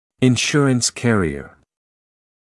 [ɪn’ʃuərəns ‘kærɪə][ин’шуэрэнс ‘кэриэ]страховая компания